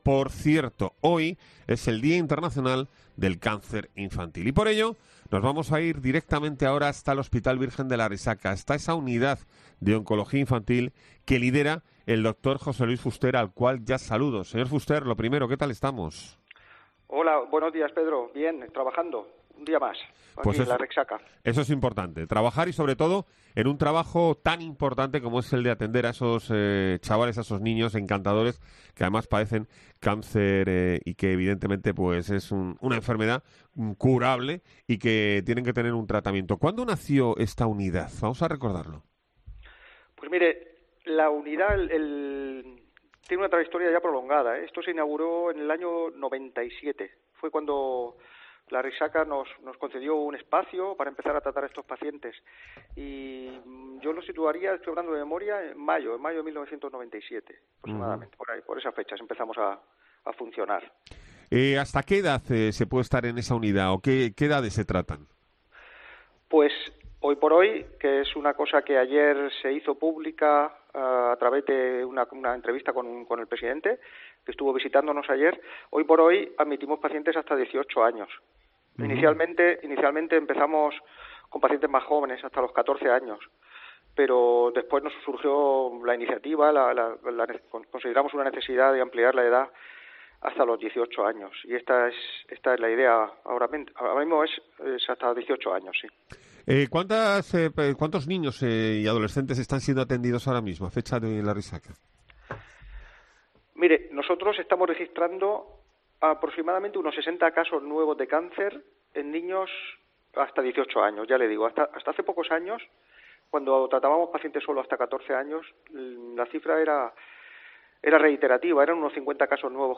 La entrevista puedes escucharla en COPE Región de Murcia